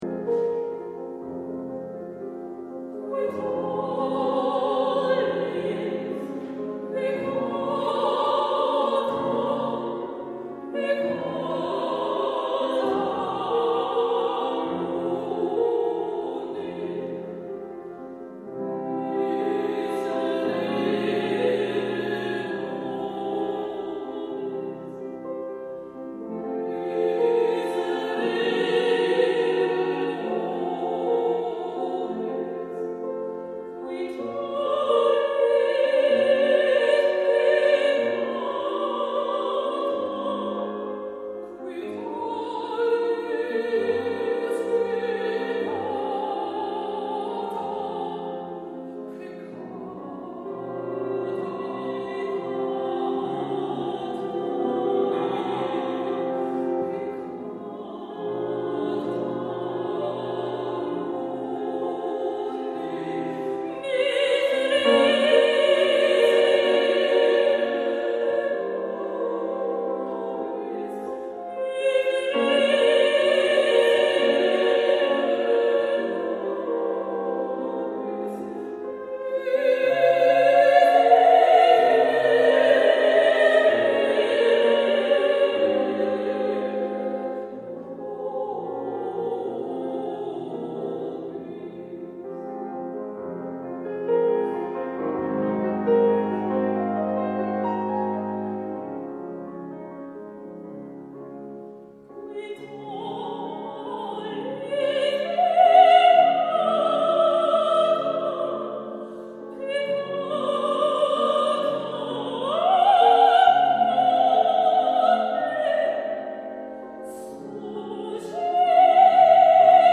Duett